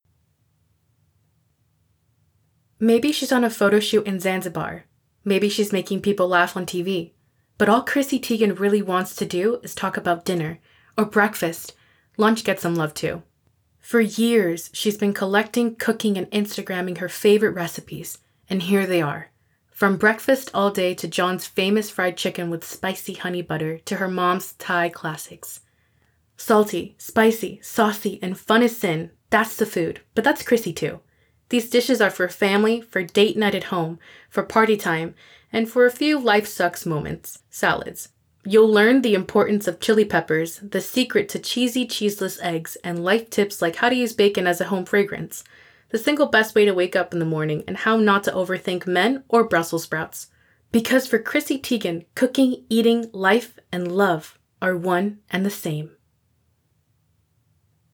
I can hear the room behind you.
The SS sounds in those phrases will cut wood without the DeEsser.
The reverb from the room is extending the sibilance.